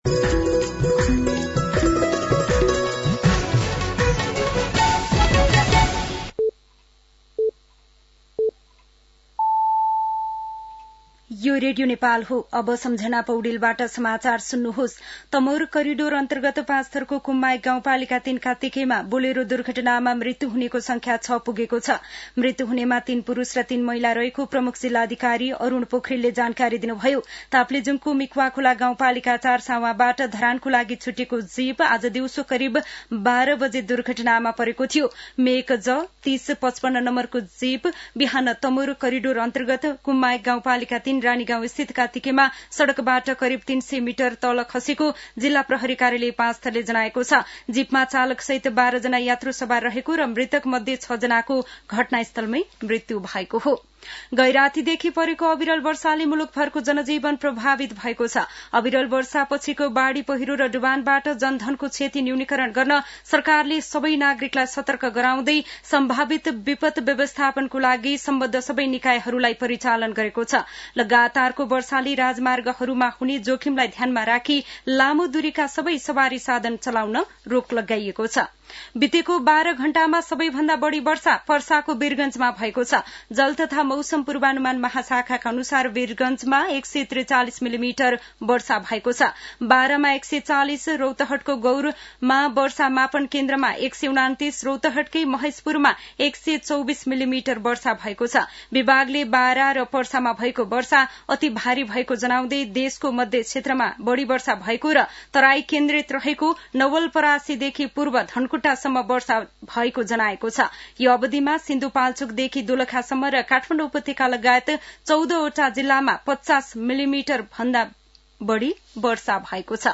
दिउँसो ४ बजेको नेपाली समाचार : १८ असोज , २०८२